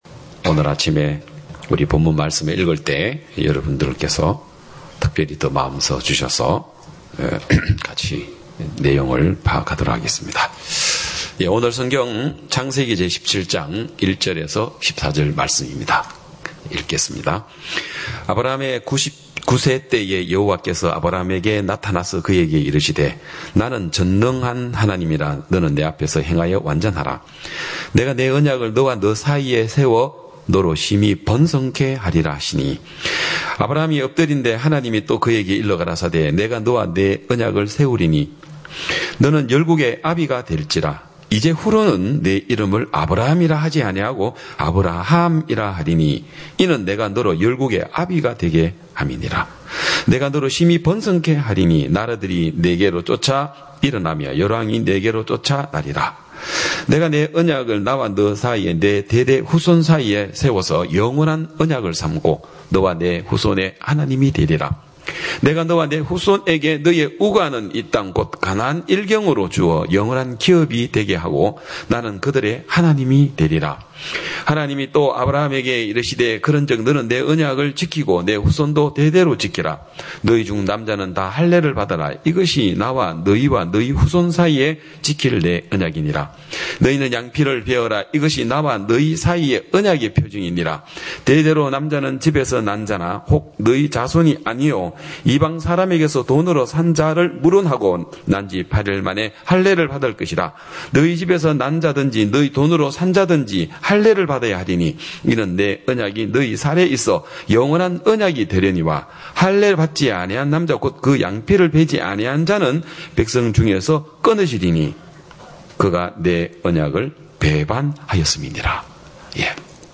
2022년 6월 5일 기쁜소식양천교회 주일오전예배